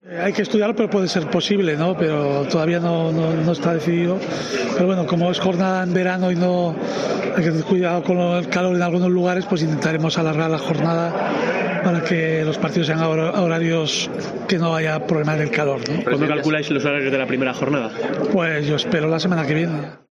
Javier Tebas habla sobre la primera jornada de Liga